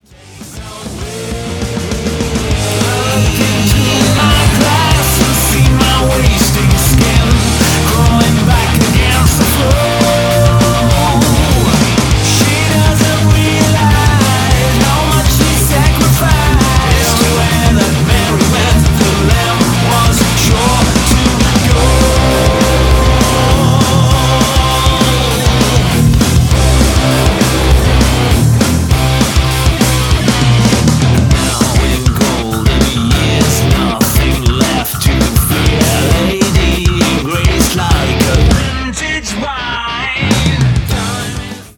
RETRO MODERNIST ALT-METAL